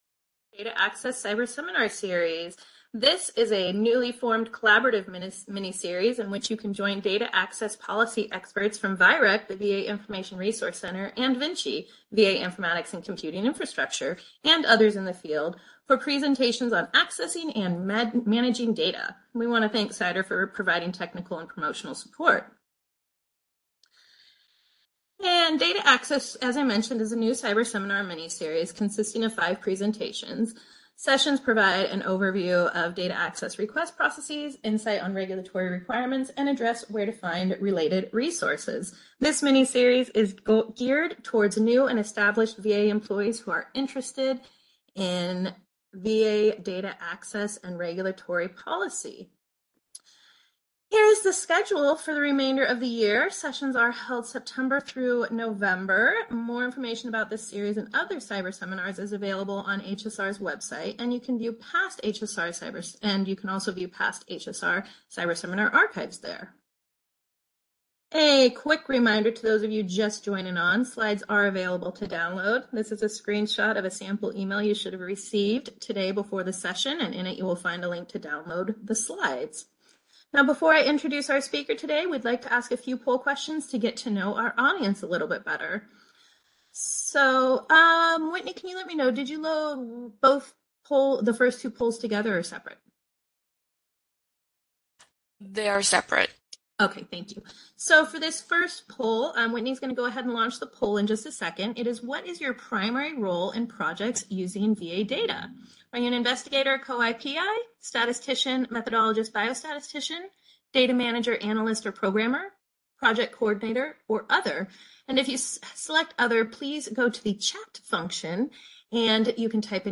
This presentation will include a demonstration of DART, how to amend a request, as well as the post-DART approval process of provisioning. Objectives: This presentation is intended to teach those wanting to do research how to request access to data for either Preparatory to Research or IRB research.